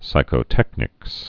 (sīkō-tĕknĭks)